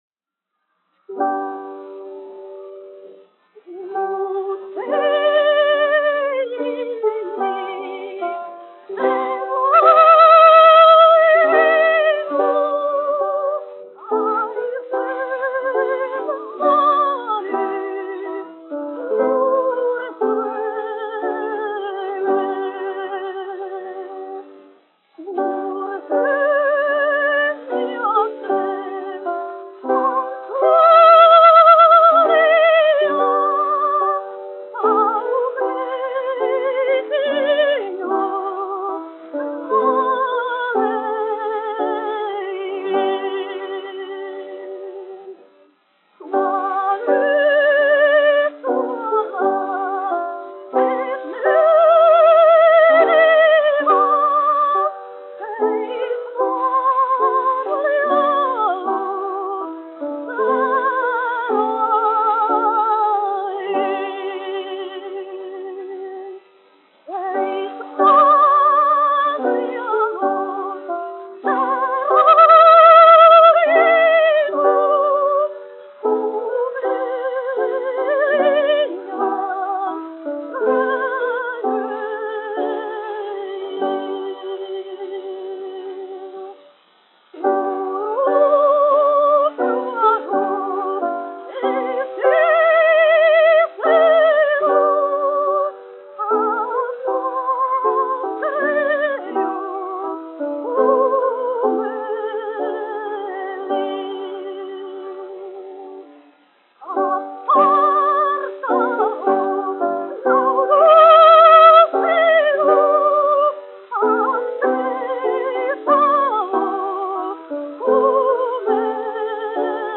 Pūt, vējiņi : ar klavieru pavadījumu
1 skpl. : analogs, 78 apgr/min, mono ; 25 cm
Latviešu tautasdziesmas